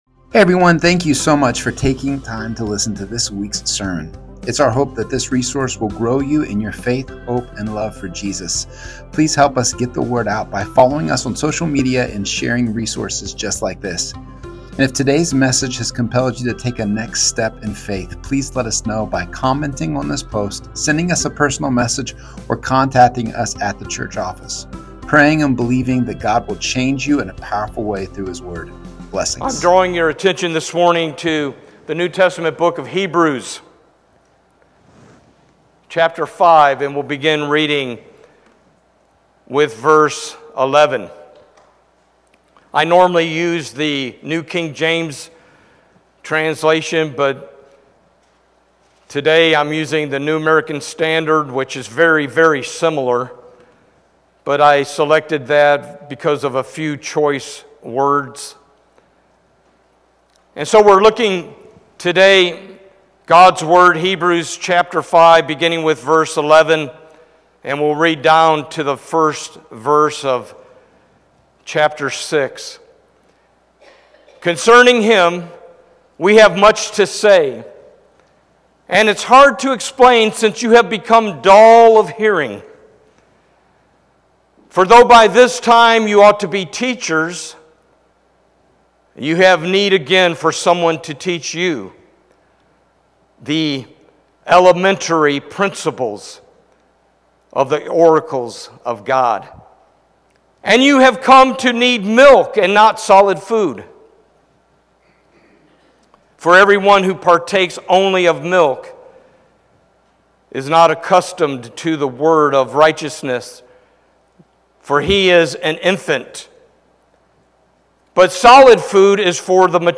Sermons | First Baptist Church of St Marys